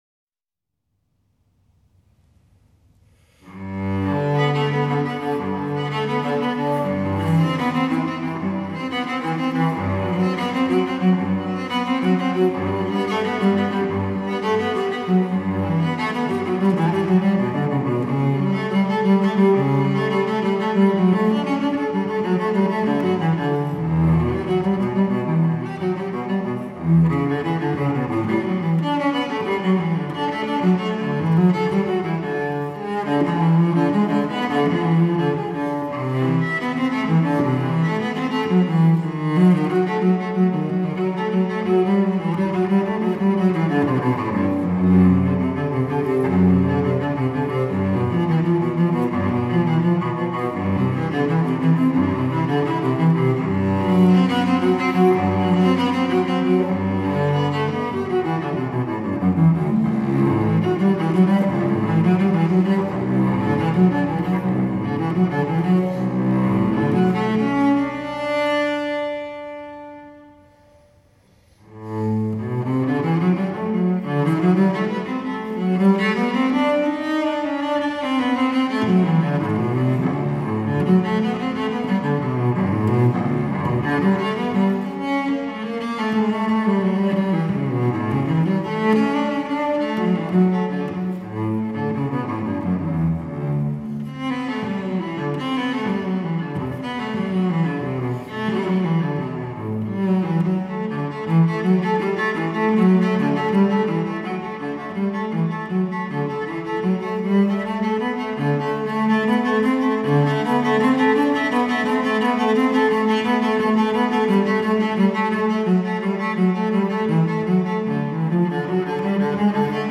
Enregistrement public au Yumeria- Hall. Shin-Totsukawa Japon